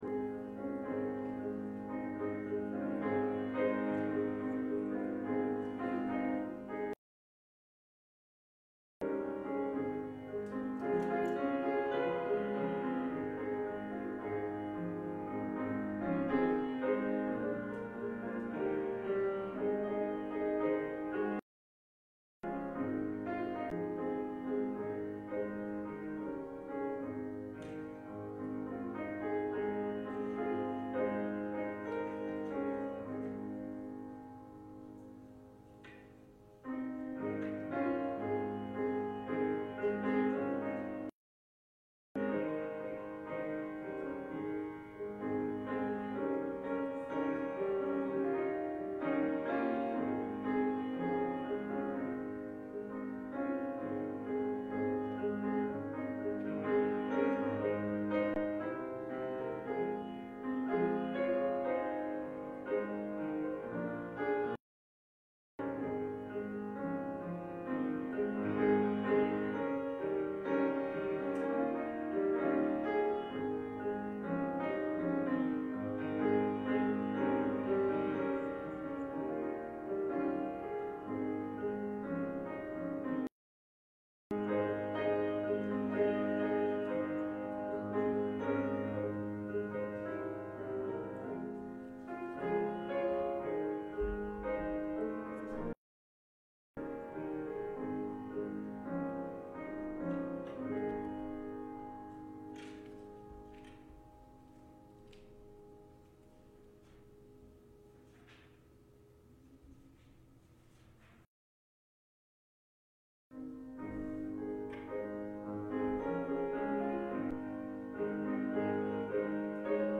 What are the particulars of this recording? Passage: Matthew 11:25-30 Service Type: Morning « Bringing the Fruit in 2021